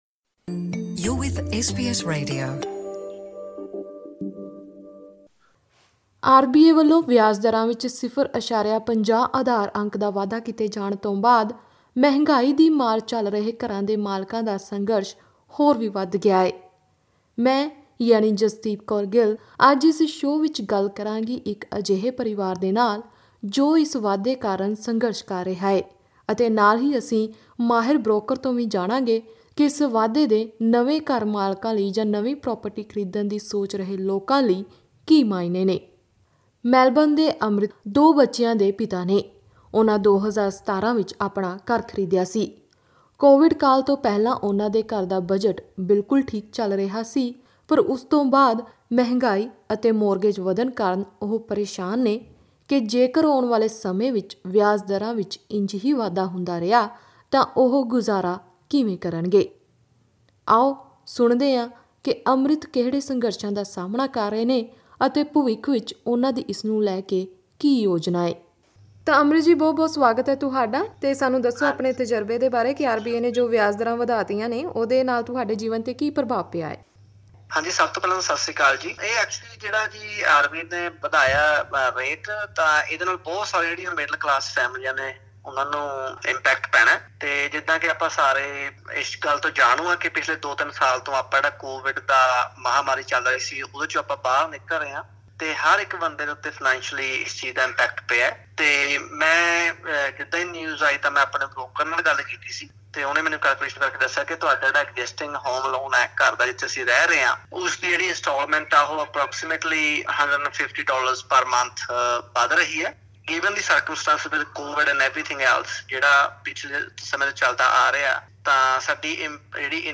ਰਿਜ਼ਰਵ ਬੈਂਕ ਆਫ਼ ਆਸਟ੍ਰੇਲੀਆ ਵੱਲੋਂ ਵਿਆਜ਼ ਦਰਾਂ ਵਿੱਚ ਕੀਤੇ ਵਾਧੇ ਤੋਂ ਬਾਅਦ ਮੌਰਟਗੇਜ ਦਰਾਂ ਵਿੱਚ ਕਿੰਨਾਂ ਕੁ ਫ਼ਰਕ ਆਇਆ ਹੈ ਅਤੇ ਇਸ ਵਾਧੇ ਪਿੱਛੋਂ ਨਵੀਂ ਪ੍ਰਾਪਰਟੀ ਖਰੀਦਣ ਵਾਲੇ ਕਿੰਨ੍ਹਾਂ ਗੱਲਾਂ ਦਾ ਧਿਆਨ ਰੱਖਣ, ਜਾਨਣ ਲਈ ਸੁਣੋ ਇਹ ਵਿਸ਼ੇਸ਼ ਆਡੀਓ ਰਿਪੋਰਟ।